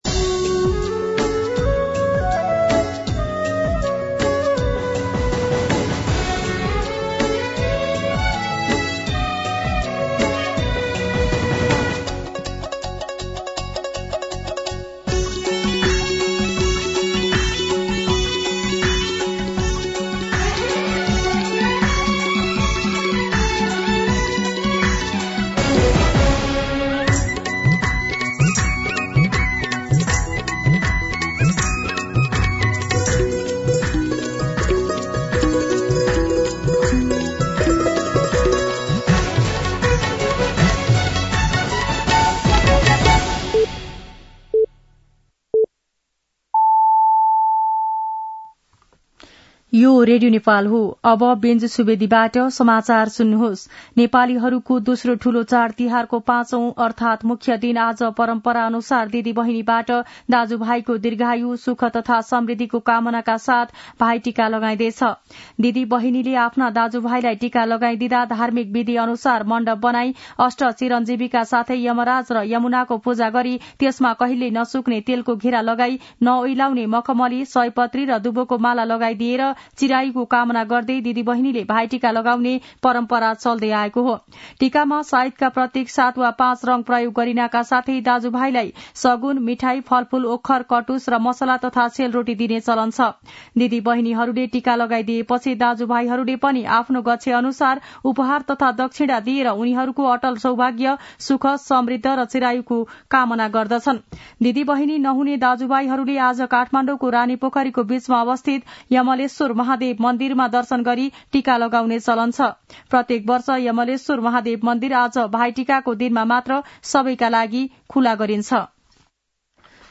An online outlet of Nepal's national radio broadcaster
मध्यान्ह १२ बजेको नेपाली समाचार : १९ कार्तिक , २०८१
12-pm-Nepali-News-.mp3